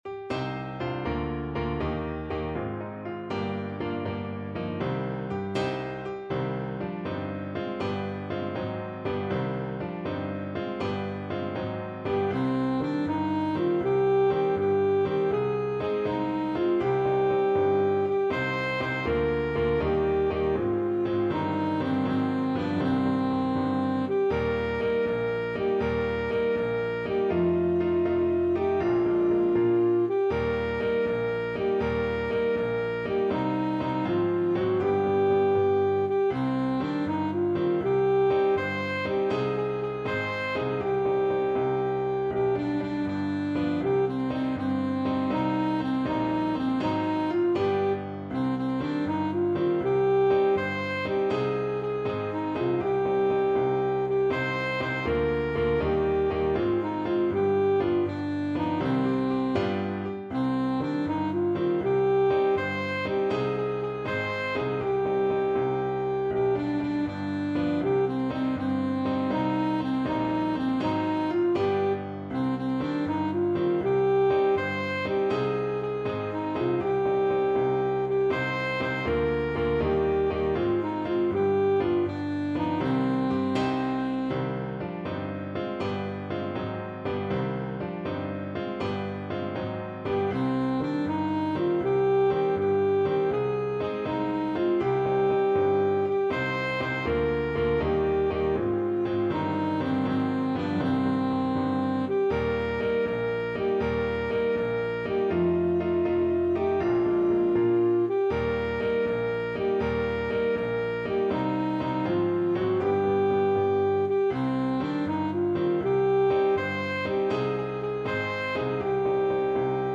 Alto Saxophone
6/8 (View more 6/8 Music)
Brightly, but not too fast